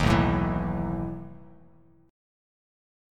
Listen to B11 strummed